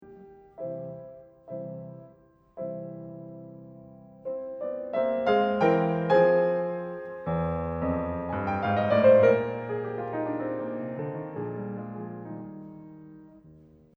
But now it’s in a more theatrical, speaking, recitative character. He also, all of a sudden, throws us into a very different tonality, C major.